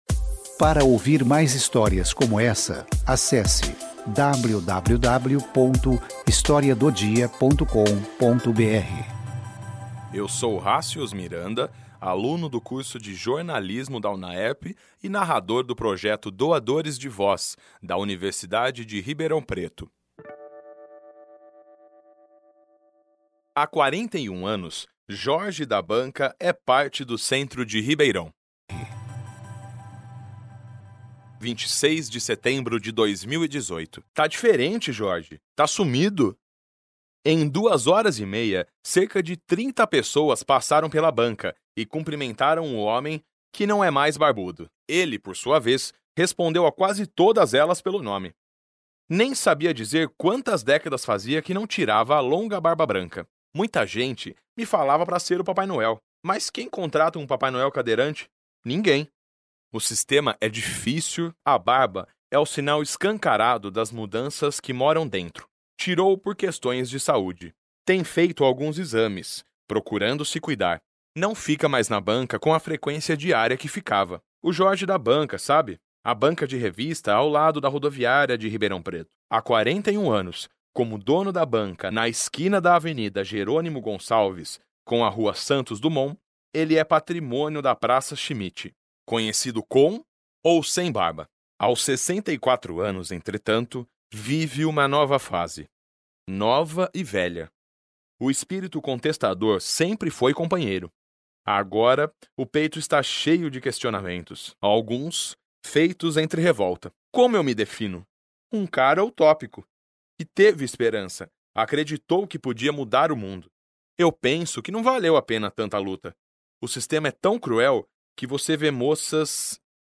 Clique no play para ouvir a história, narrada pelos integrantes do projeto Doadores de Voz, dos cursos de Jornalismo e Publicidade e Propaganda da Unaerp: